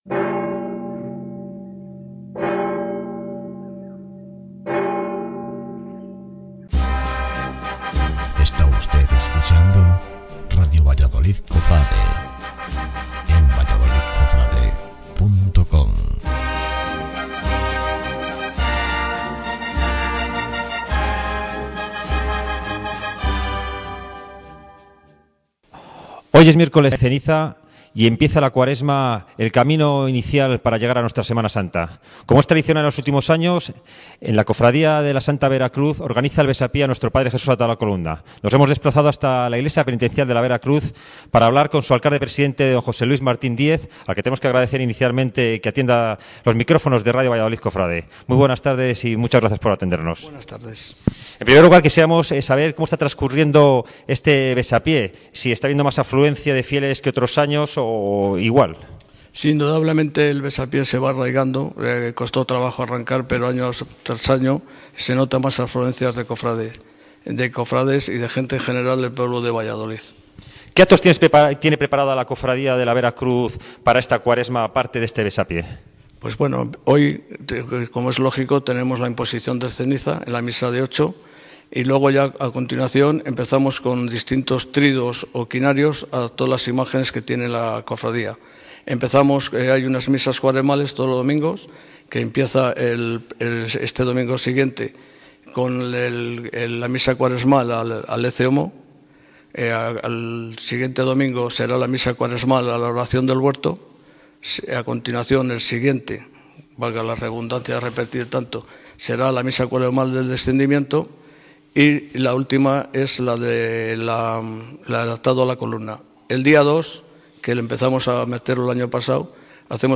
Radio ValladolidCofrade se acercó al Besapié del Cristo Atado a la Columna